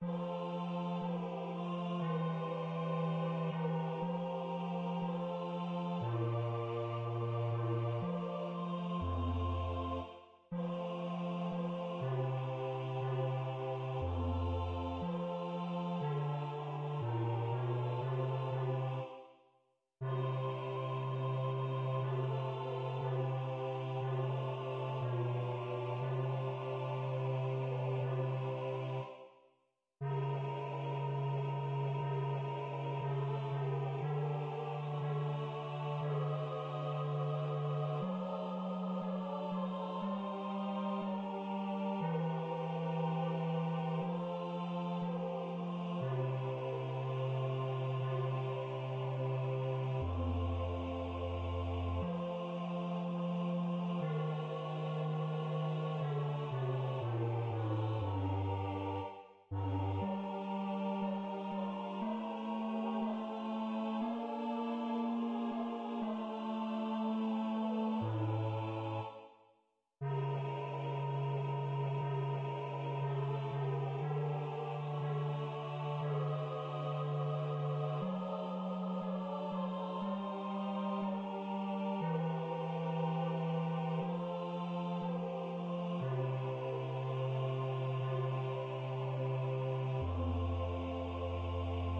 pdmx-multi-instrument-synthesized